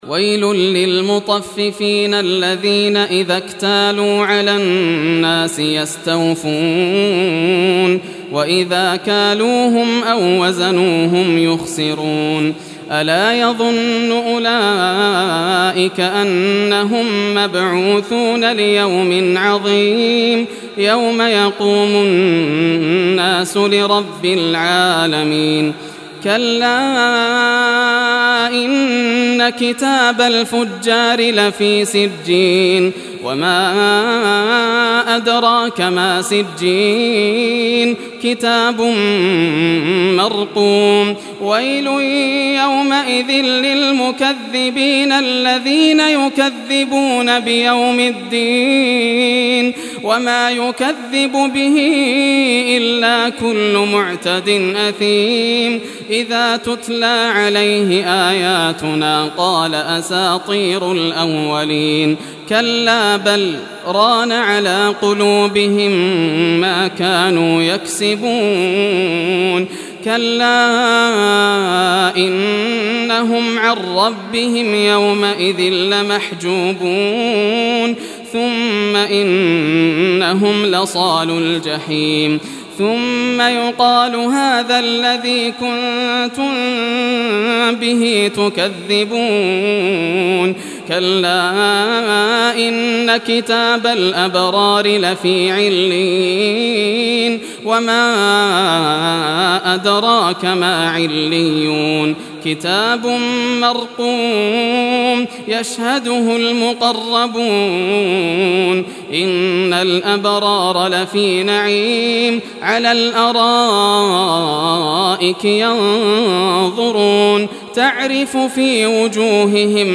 Surah Al-Mutaffifin Recitation by Yasser al Dosari
Surah Al-Mutaffifin, listen or play online mp3 tilawat / recitation in Arabic in the beautiful voice of Sheikh Yasser al Dosari.
83-surah-mutaffifin.mp3